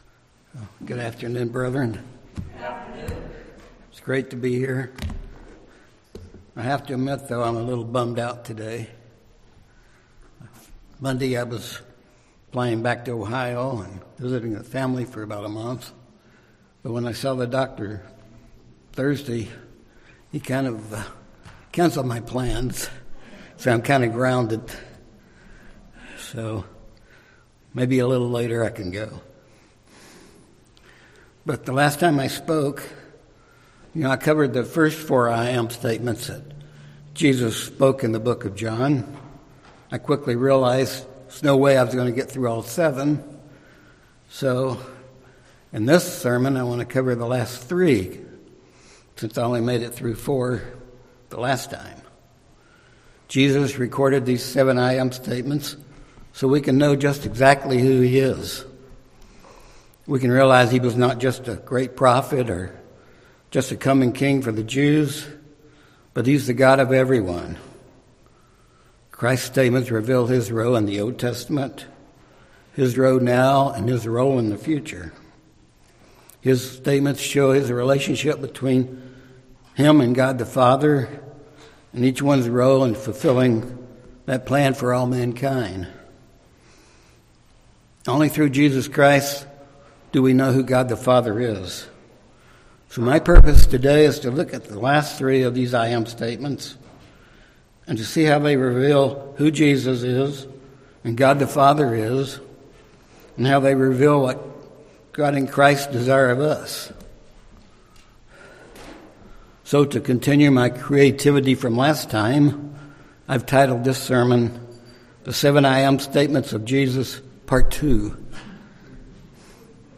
This sermon looks at the last 3 "I AM" statements of Jesus Christ and how these statements reveal who Jesus is, who God the Father is, and the relationship between them both. They also reveal what Christ and God the Father desire of us and shows us what we need to learn from these statements if we are going to be in God's kingdom.